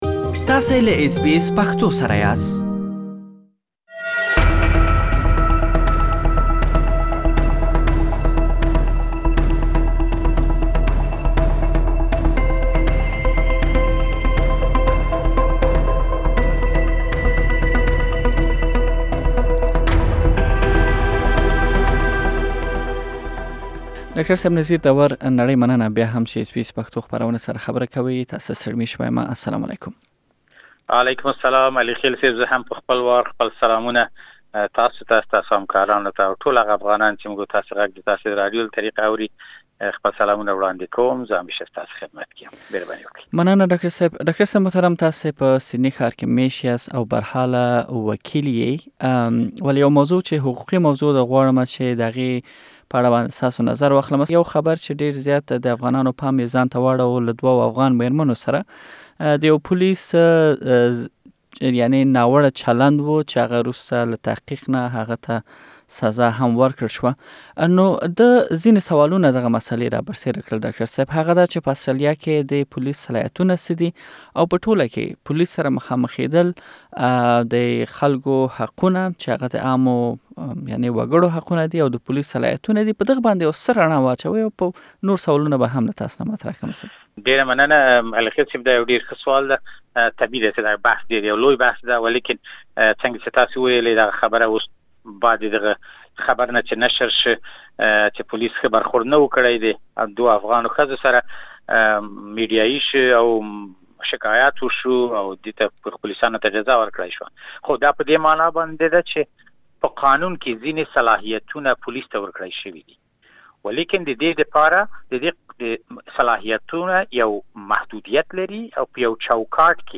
Source: Supplied تاسې پوليس پر وړاندې خپل حقونو او هم پوليس صلاحيتونو په اړه بشپړه مرکه دلته واورئ.